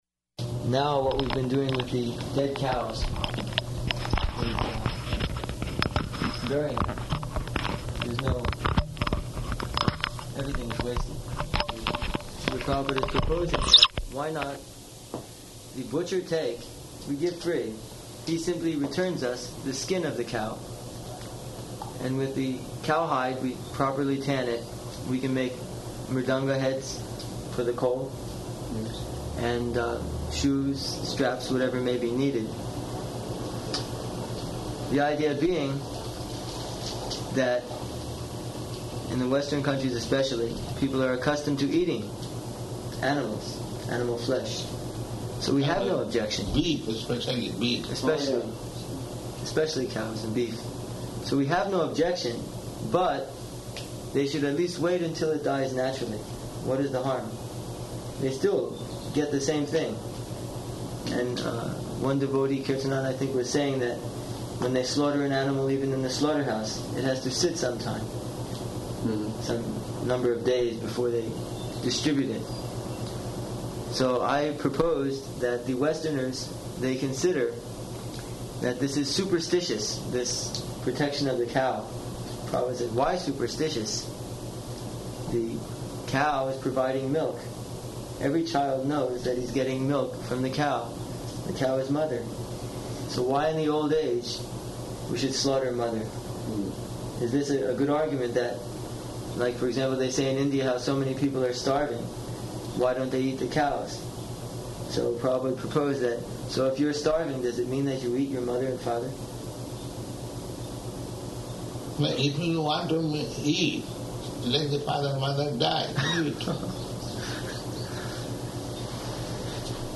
Room Conversation
-- Type: Conversation Dated: June 29th 1976 Location: New Vrindavan Audio file